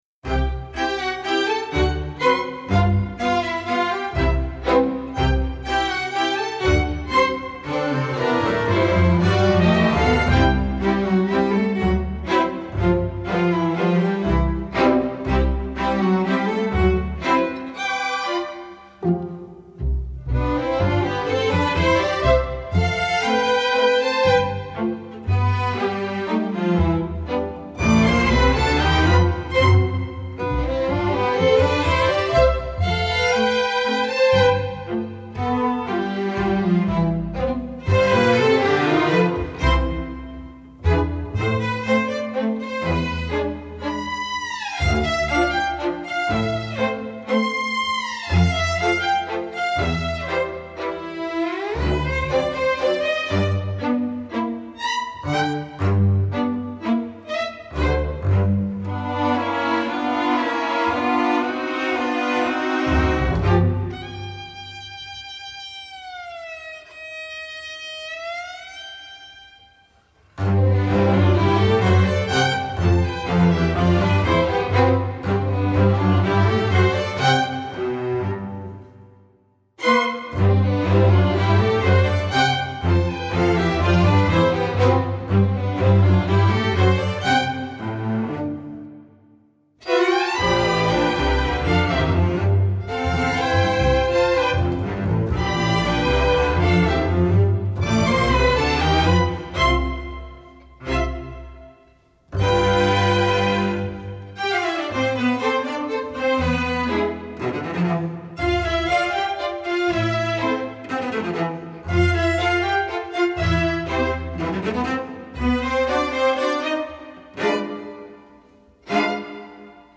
Instrumentation: string orchestra (full score)